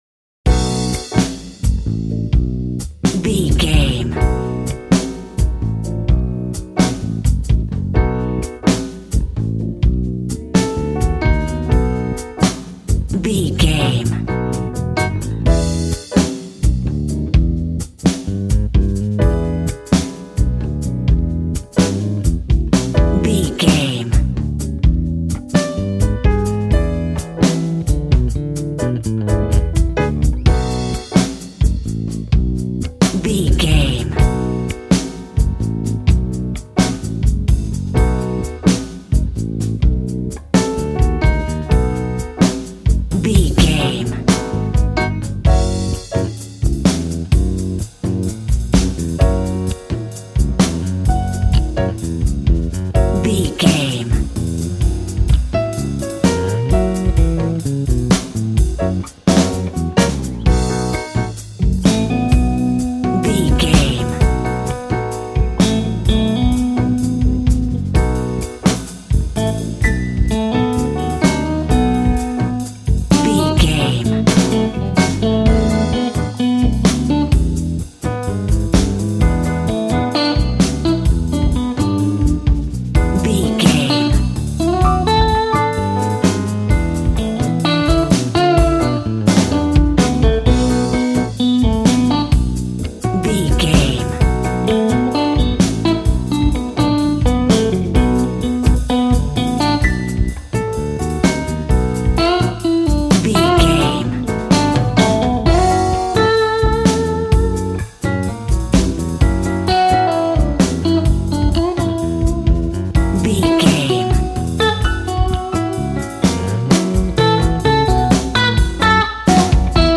Blues music with a Bossa Nova Feel.
Aeolian/Minor
G♭
sad
mournful
bass guitar
electric guitar
electric organ
drums